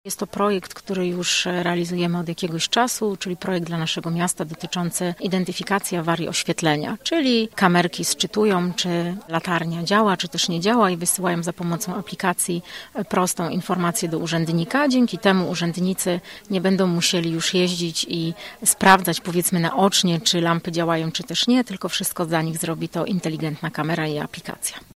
O rozwoju Internetu Rzeczy rozmawiali dziś uczestnicy konferencji zorganizowanej w Wojewódzkiej i miejskiej Bibliotece Publicznej. Celem spotkania było upowszechnienie informacji na temat możliwości pozyskiwania znacznych środków finansowych na inteligentne rozwiązania związane z Internetem Rzeczy czyli współpracy różnych urządzeń przy pomocy internetu.